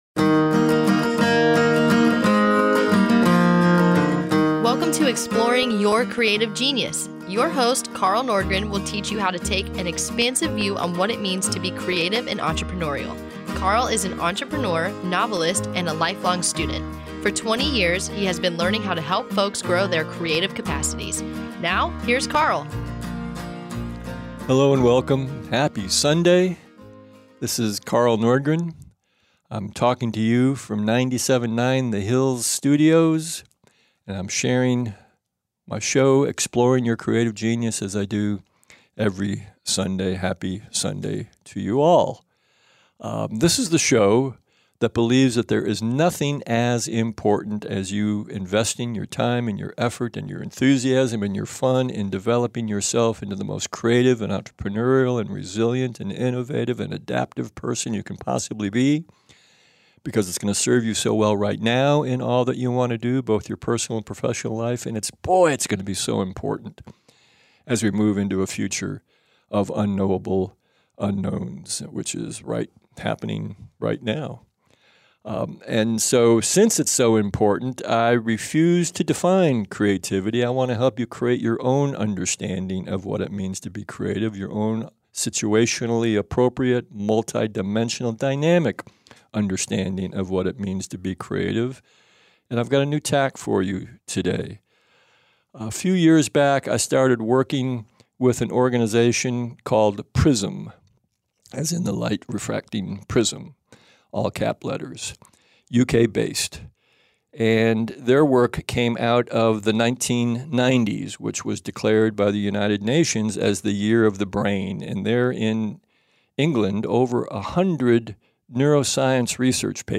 For this column I’m not going to revisit this radio show. It’s a show about how important it is to allow folks to use their preferred behaviors when working on a project, by the way.